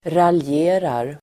Ladda ner uttalet
Uttal: [ralj'e:rar]